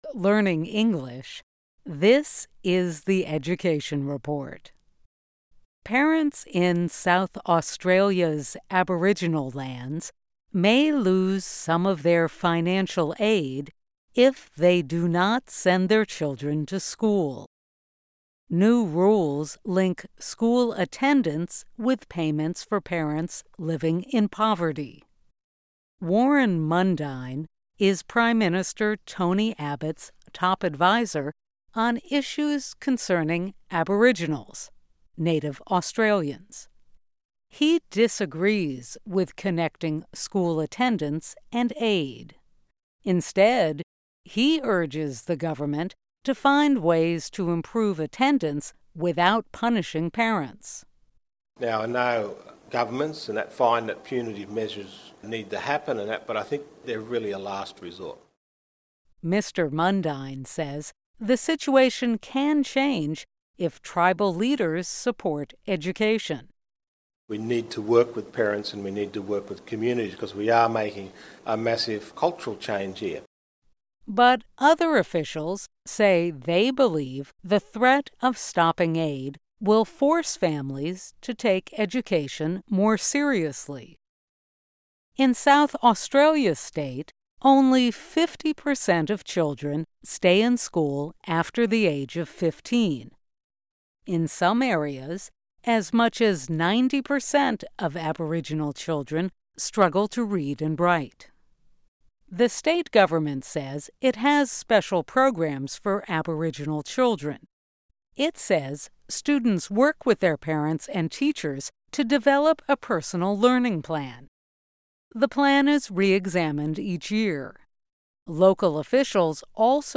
Learn English as you read and listen to a weekly show about education, including study in the U.S. Our stories are written at the intermediate and upper-beginner level and are read one-third slower than regular VOA English.